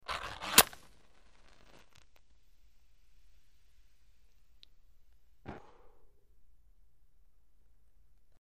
Sizzle; Match Strike, Ignite Flame And Blowout Close Perspective #6-7: Very Close Strike And Light, X7